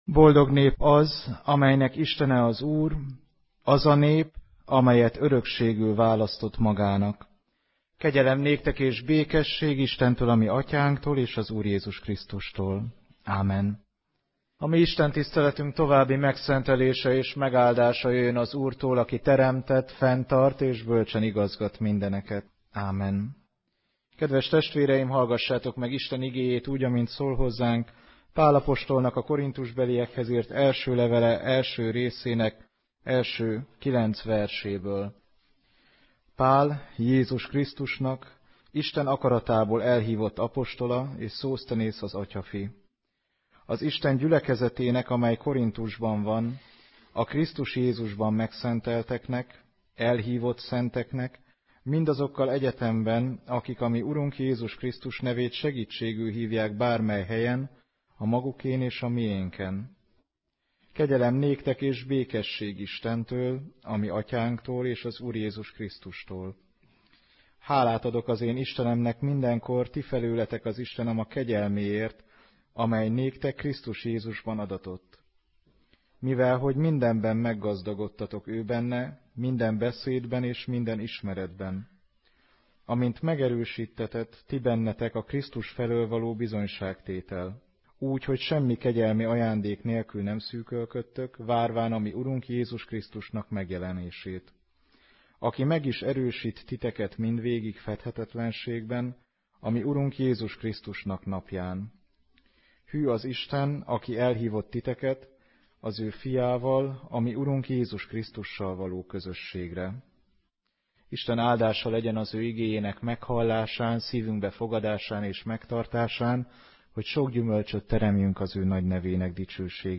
2015 március 15, vasárnap délelőtt; Nemzeti Ünnep